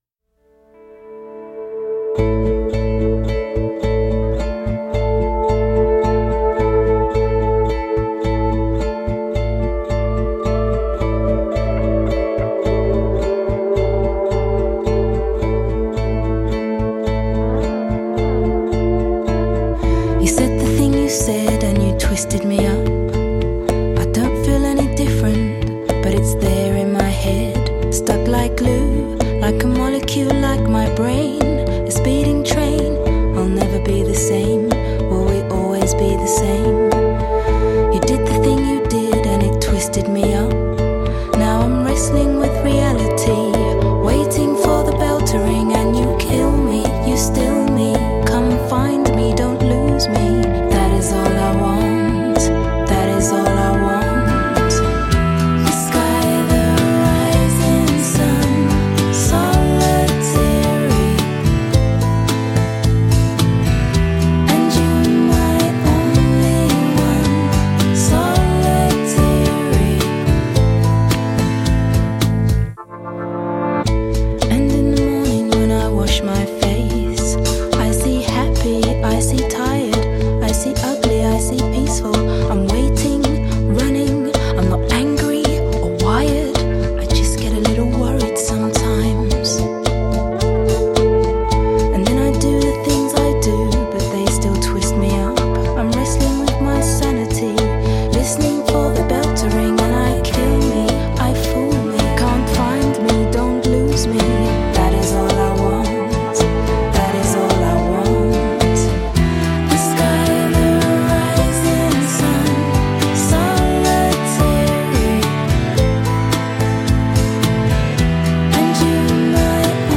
has a voice as smooth as silk.